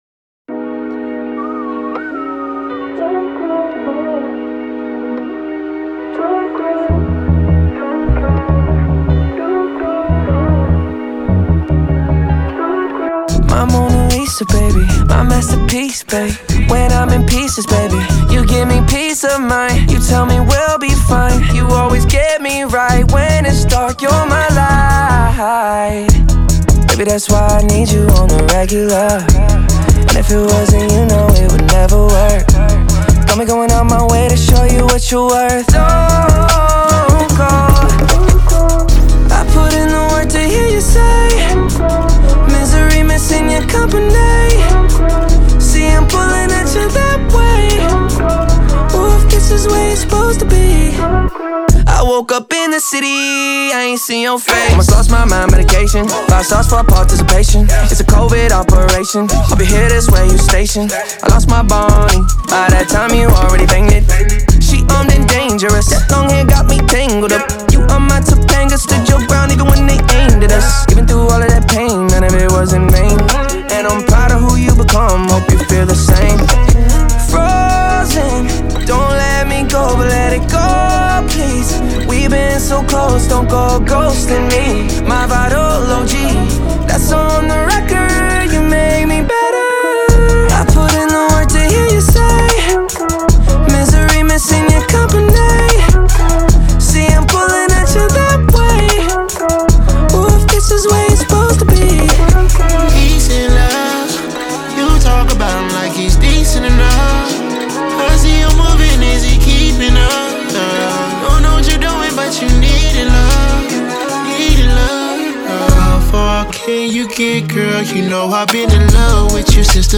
BPM150-150
Audio QualityPerfect (High Quality)
Trap song for StepMania, ITGmania, Project Outfox
Full Length Song (not arcade length cut)